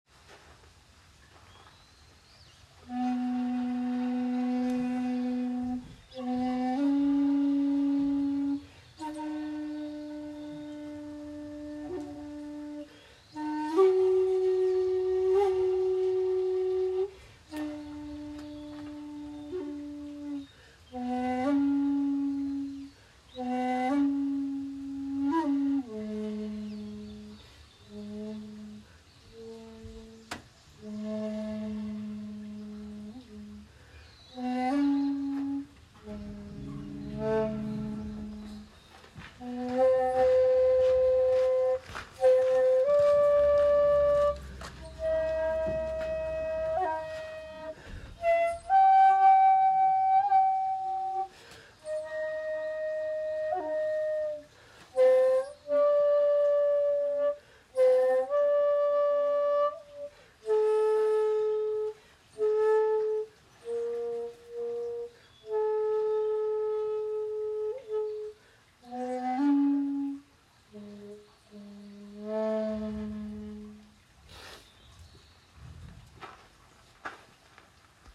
お参りをして尺八を吹奏しました。
◆◆　（尺八音源：藤井寺にて「水鏡」）
553-藤井寺.m4a